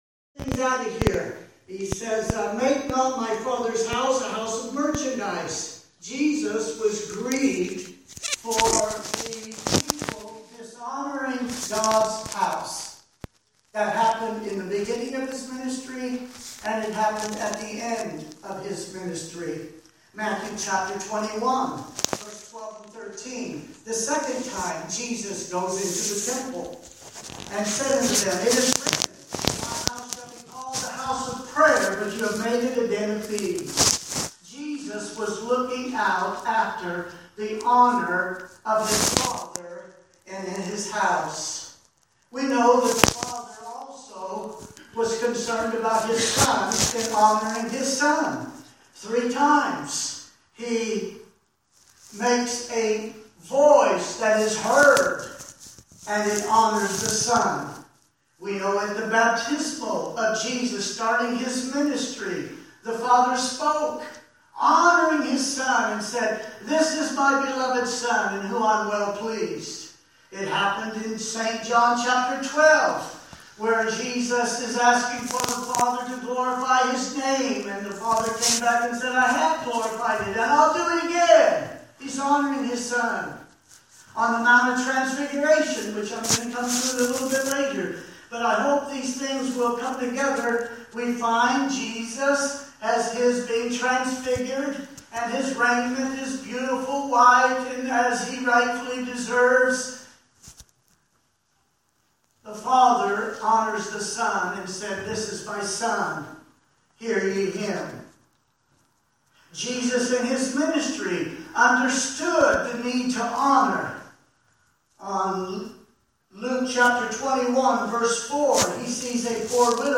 Please excuse the poor audio quality this week!
Morning Sermons